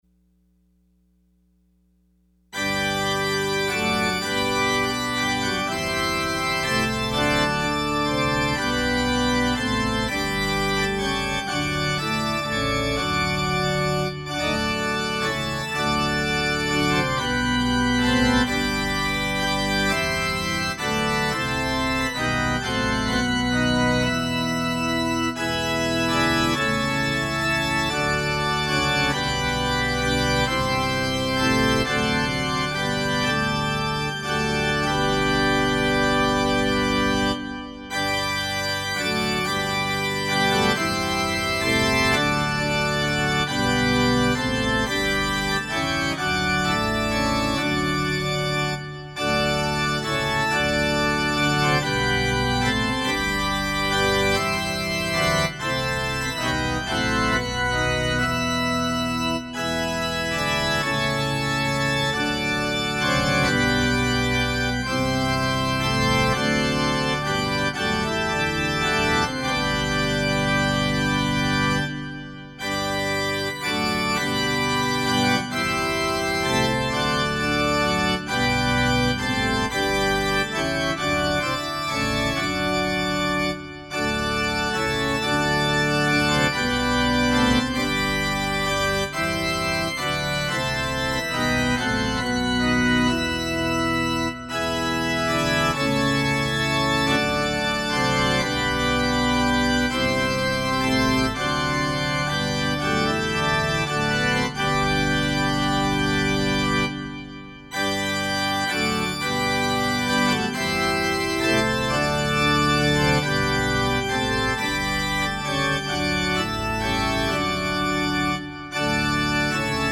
Closing Hymn – #518 – Christ is made the sure foundation